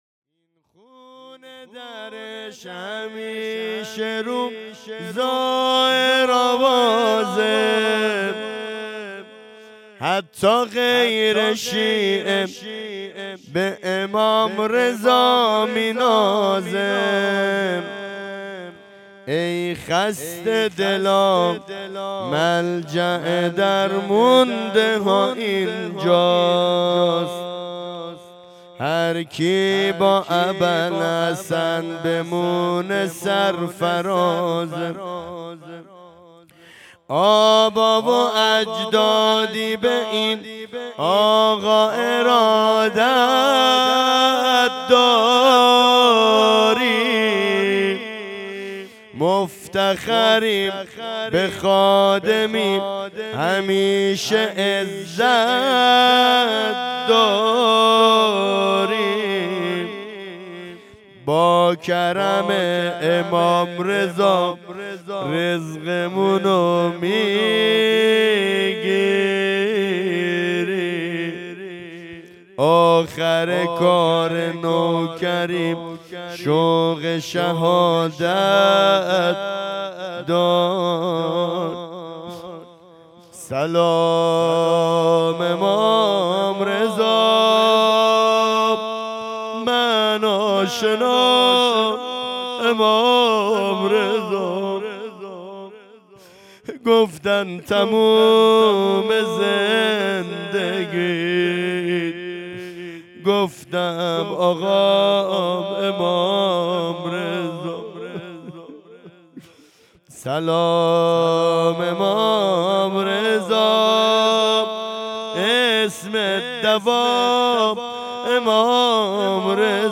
جشن دهه کرامت 1404
زمزمه امام رضایی